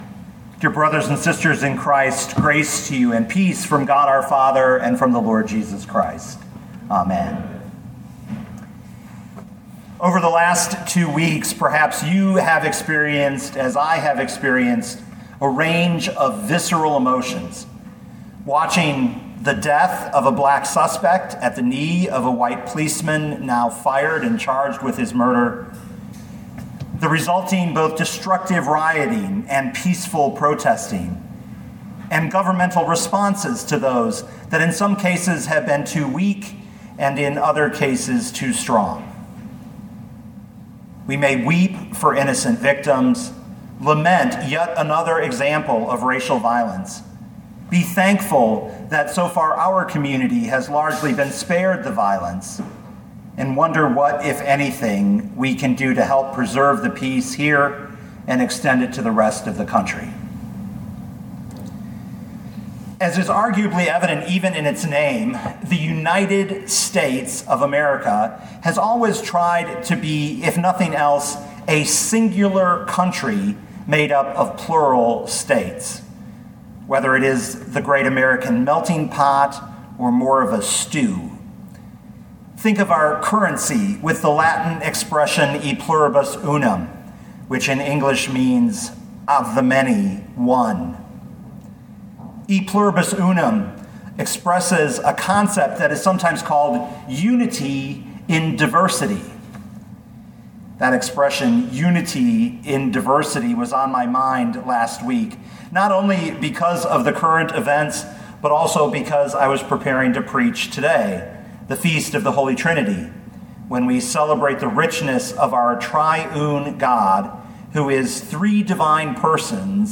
2020 Matthew 28:16-20 Listen to the sermon with the player below, or, download the audio.